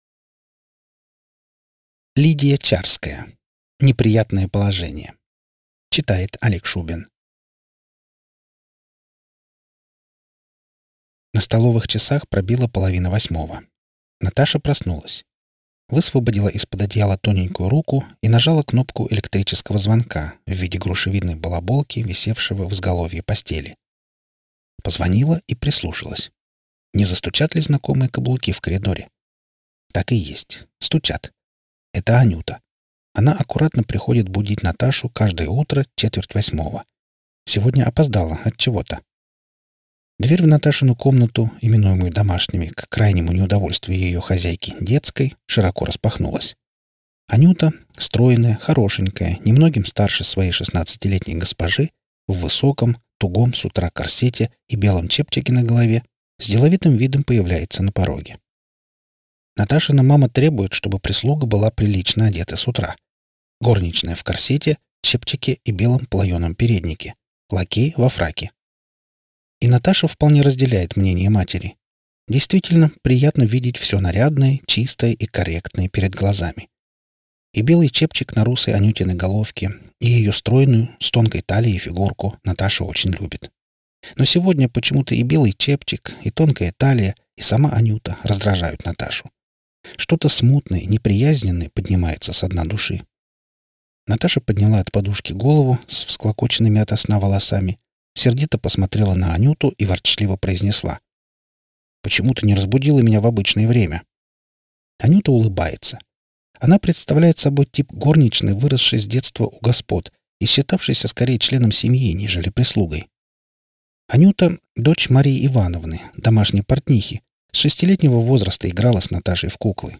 Аудиокнига